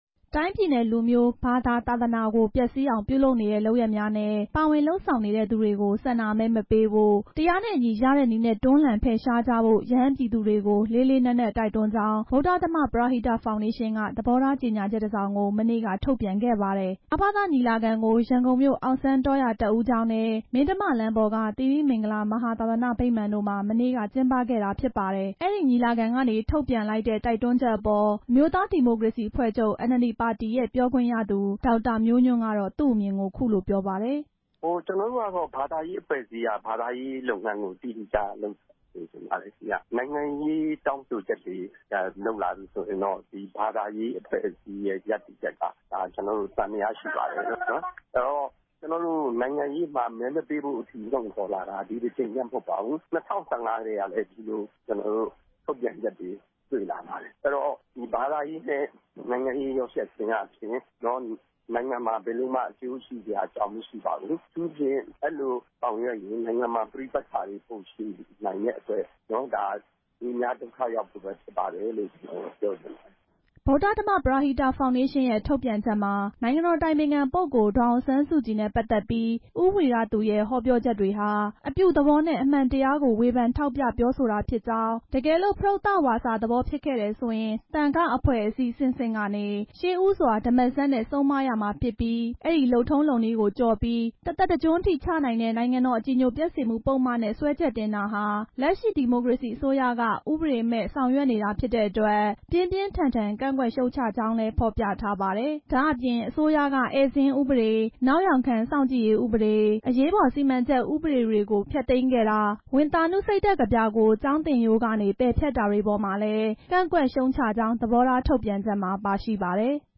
NLD ပါတီပြောခွင့်ရသူ၊ ဥပဒေပညာရှင်တွေနဲ့ နိုင်ငံရေးလေ့လာသူတွေရဲ့ သဘောထားတွေကို